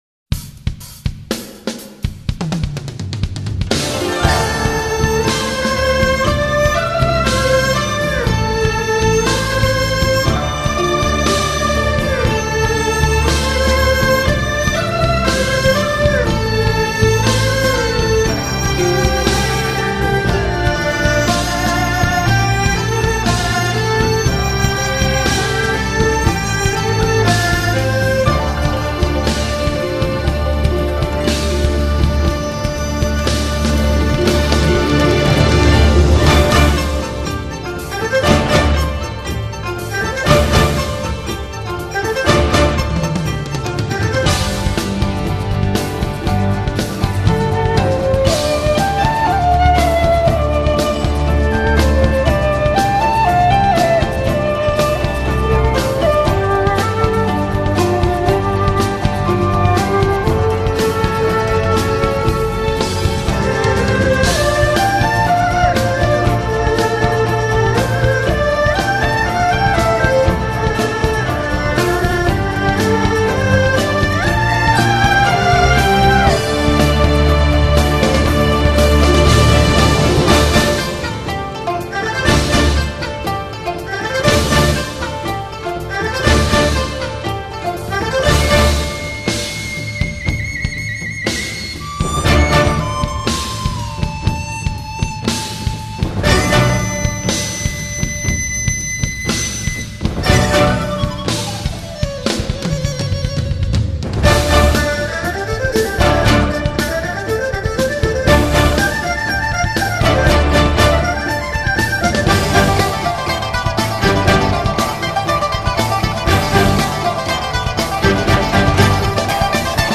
一種充滿現代氣息的聲音，如隨風潛入夜的春雨
專為汽車音響量身定做的HI-FI唱片，